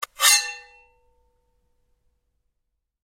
Звуки меча
Звук вынимания меча из ножен специального чехла